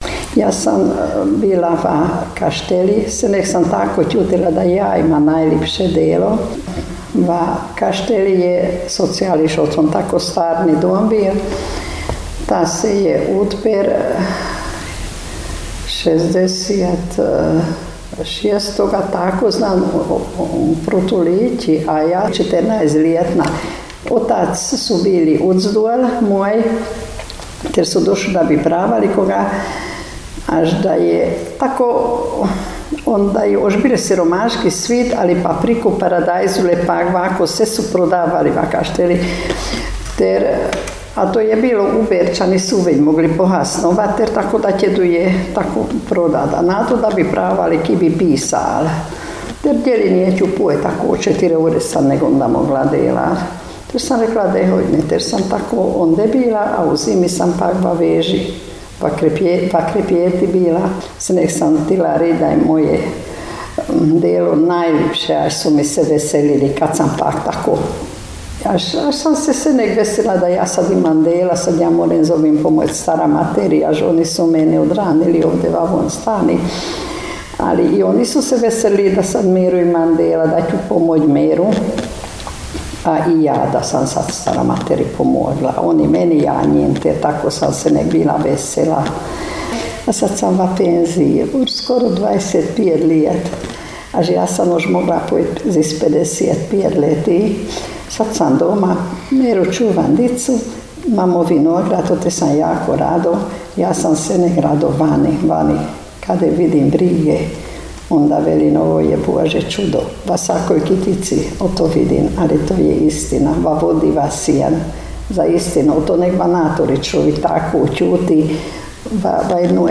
jezik naš, jezik naš gh dijalekti
Prisika – Govor – Djelo, dica, jilo
Prisika_govor-2.mp3